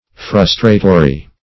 Search Result for " frustratory" : The Collaborative International Dictionary of English v.0.48: Frustratory \Frus"tra*to*ry\, a. [L. frustratorius: cf. F. frustratoire.]